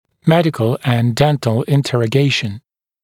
[‘medɪkl ənd ‘dentl ɪnˌterə’geɪʃn][‘мэдикл энд ‘дэнтл инˌтэрэ’гейшн]беседа с пациентом для определения соматического и стоматологического анамнеза